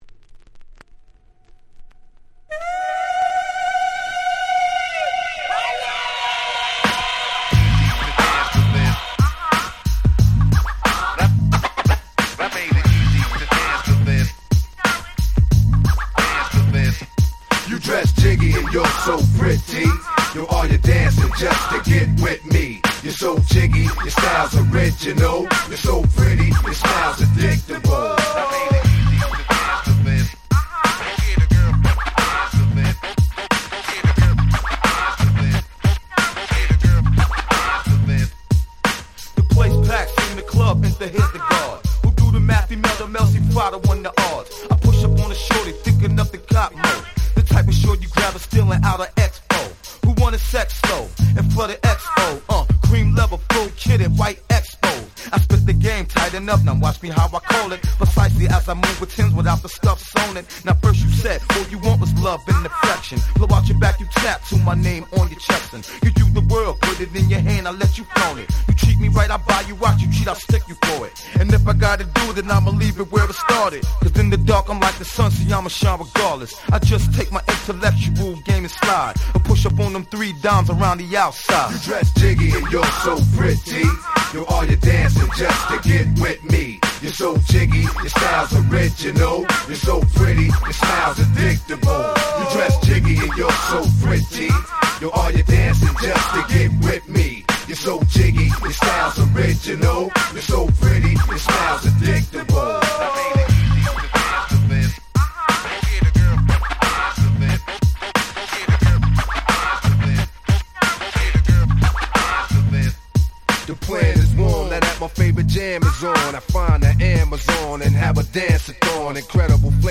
80's Old School オールドスクール